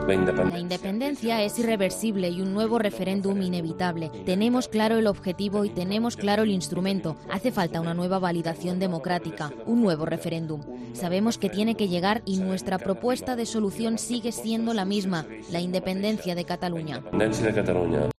El líder de ERC afirma en un mensaje grabado desde la cárcel que "la victoria no es una opción" sino "una obligación"
El líder de ERC, Oriol Junqueras, afirma en un mensaje grabado desde la cárcel y difundido en el Congreso de este partido que la independencia de Cataluña y un nuevo referéndum "son inevitables", pero ha pedido sumar más apoyos porque "la victoria no es una opción" sino "una obligación".